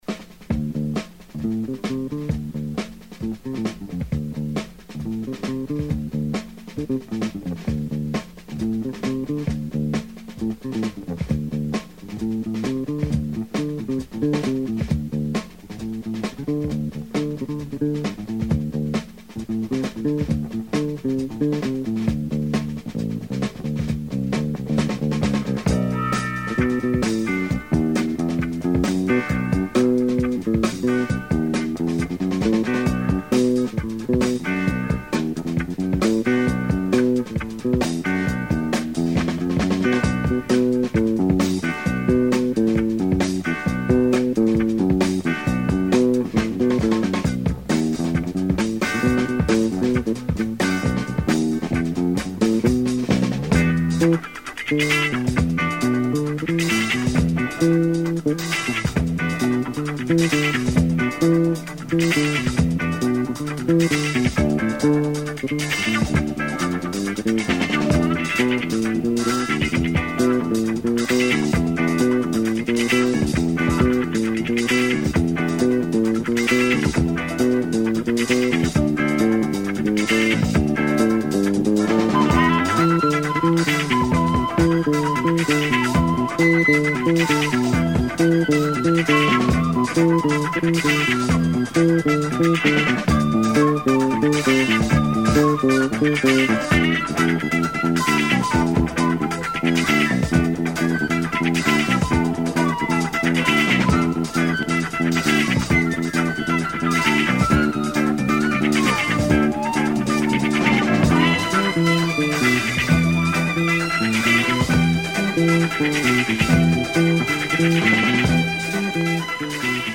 a funky wah wah chase track.